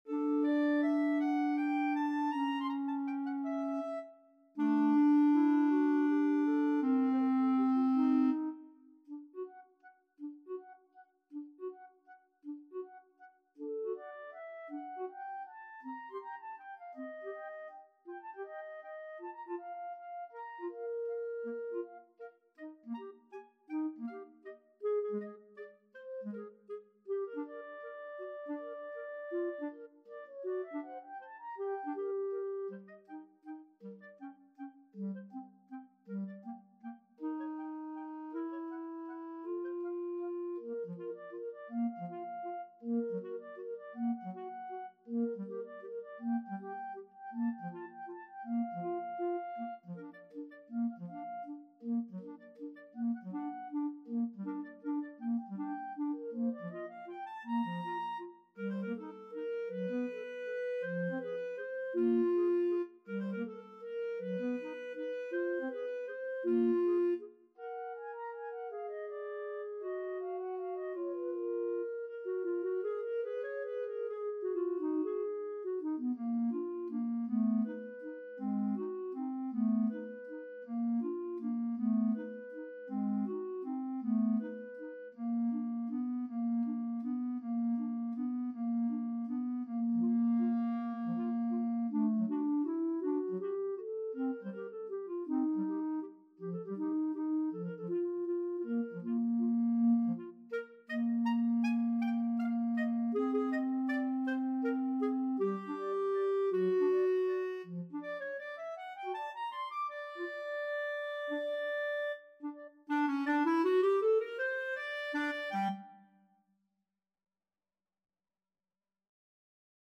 Allegretto = 160
3/4 (View more 3/4 Music)
Clarinet Duet  (View more Advanced Clarinet Duet Music)
Classical (View more Classical Clarinet Duet Music)